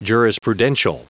Prononciation du mot jurisprudential en anglais (fichier audio)
Prononciation du mot : jurisprudential
jurisprudential.wav